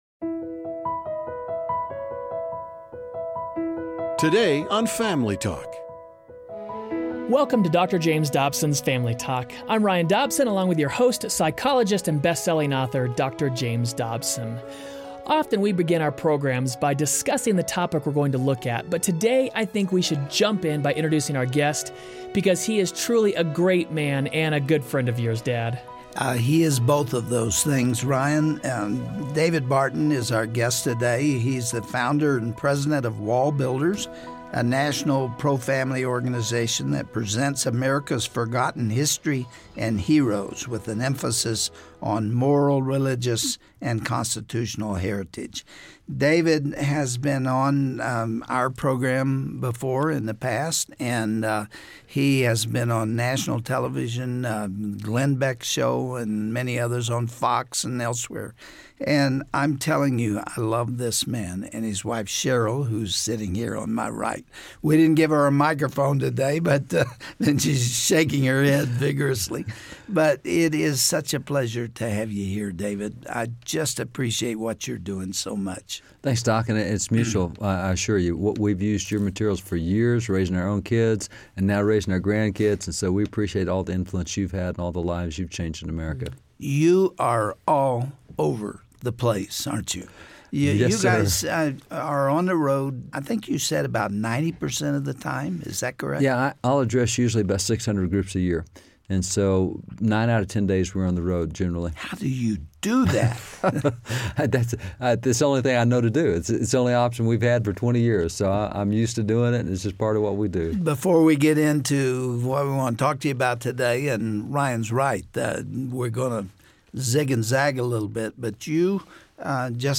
If so, how much did the Bible shape our nation? Dr. James Dobson interviews David Barton about the founding fathers of our nation.